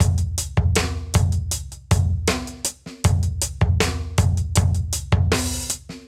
Index of /musicradar/sampled-funk-soul-samples/79bpm/Beats
SSF_DrumsProc2_79-02.wav